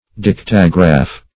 dictagraph - definition of dictagraph - synonyms, pronunciation, spelling from Free Dictionary Search Result for " dictagraph" : The Collaborative International Dictionary of English v.0.48: Dictagraph \Dic"ta*graph\ (d[i^]k"t[.a]*gr[.a]f).